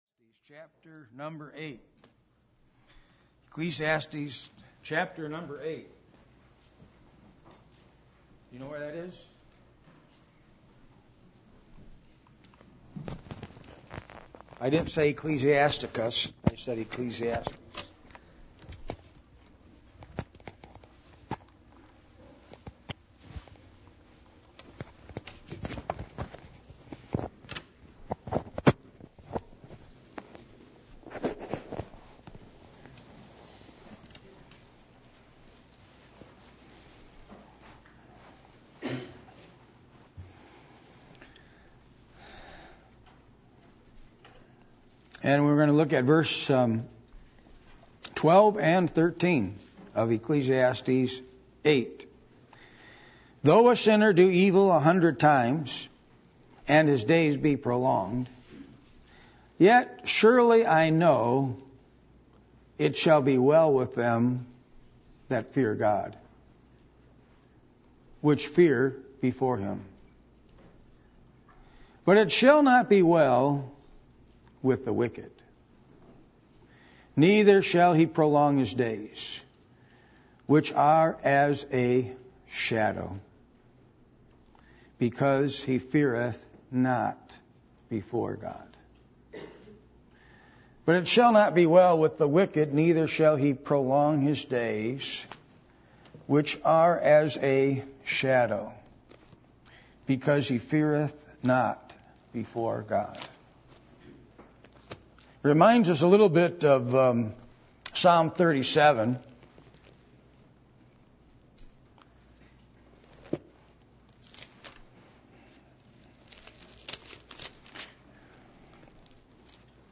Passage: Ecclesiastes 8:12-13 Service Type: Midweek Meeting